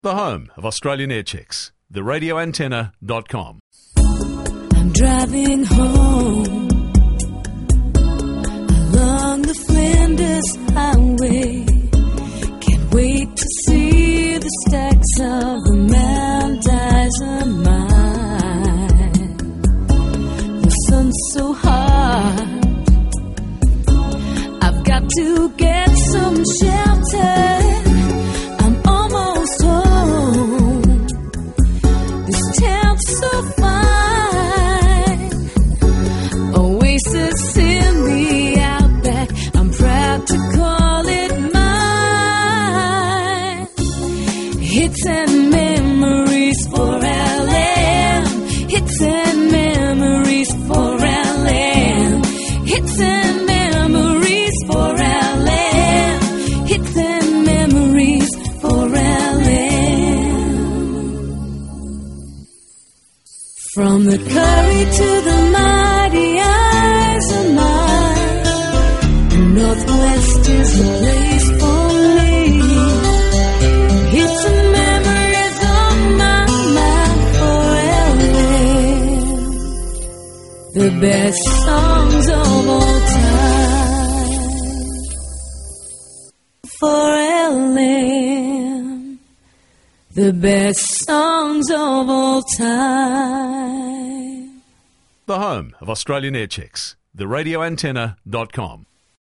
Great jingles from the outback